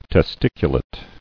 [tes·tic·u·late]